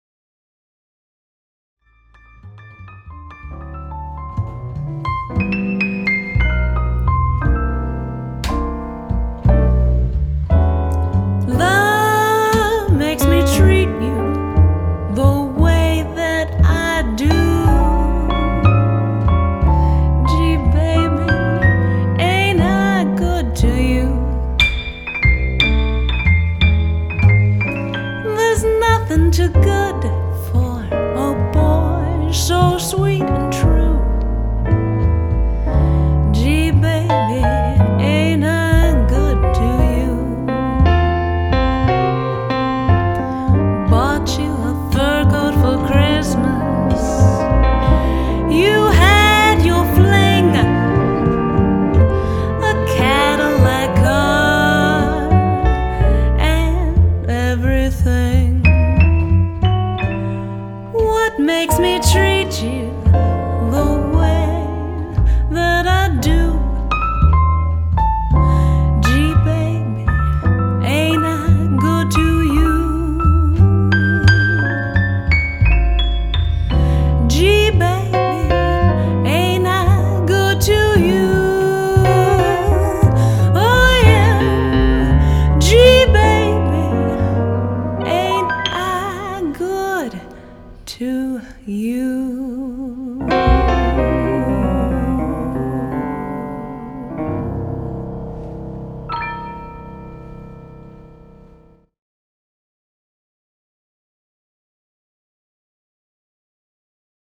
piano
bass.